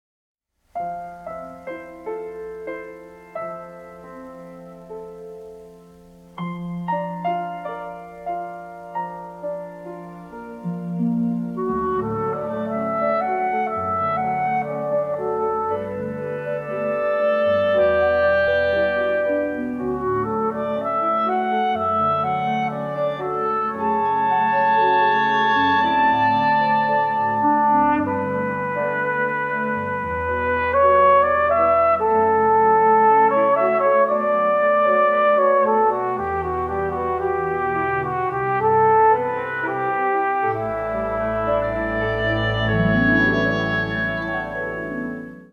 synthesizers morphed with traditional orchestral instruments